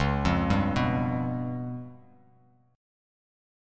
C#7b5 chord